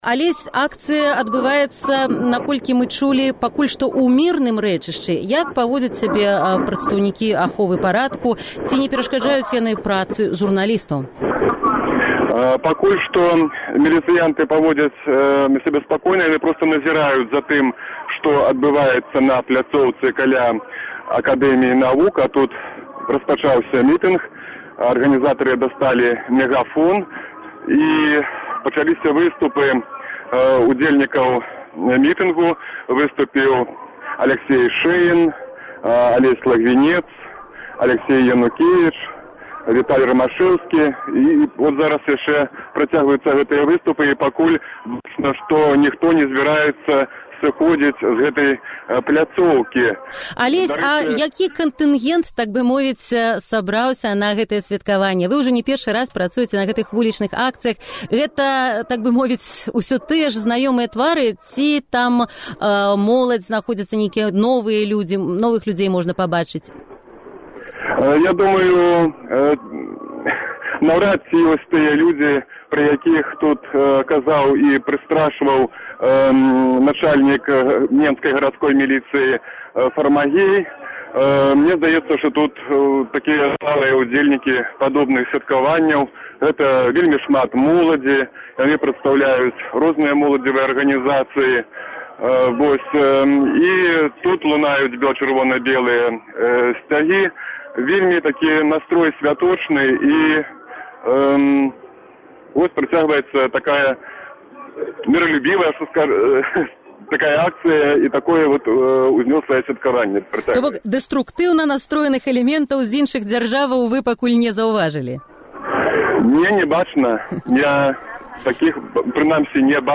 Гутарка